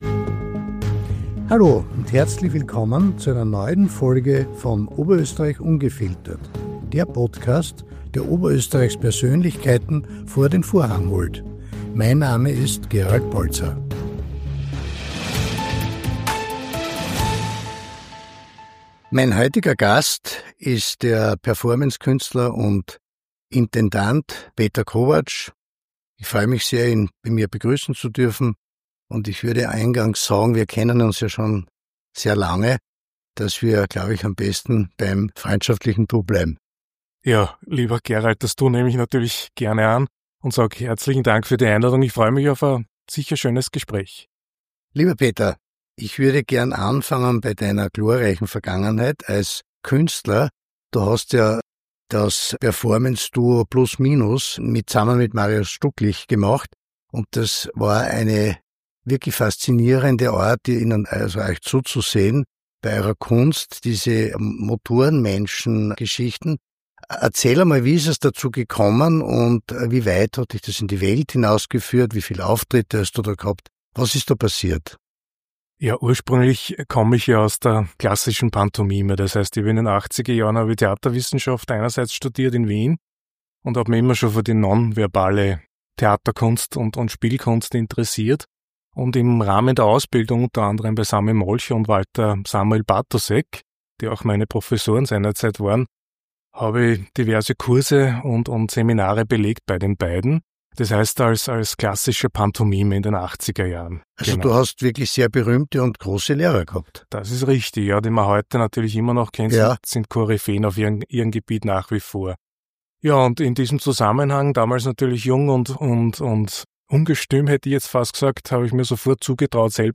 OÖ Ungefiltert erleben die Hörer ein inspirierendes Gespräch